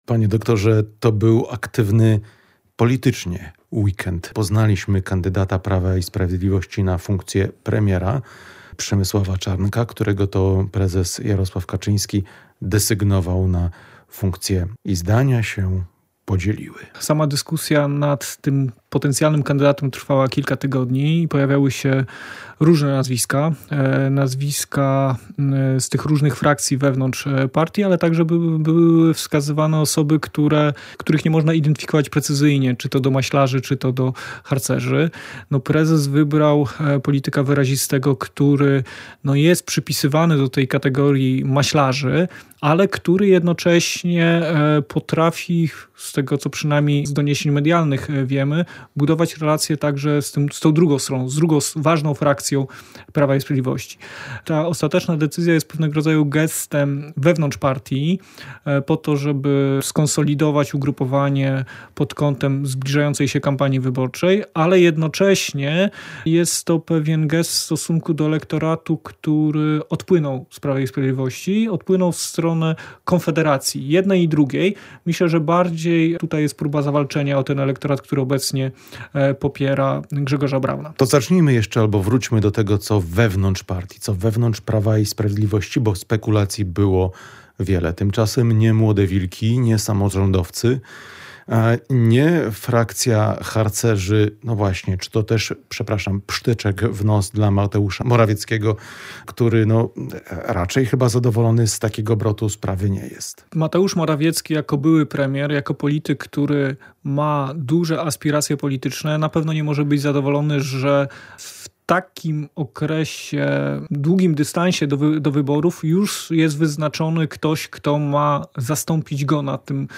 Ekspert: Wskazanie Przemysława Czarnka to zagranie all-in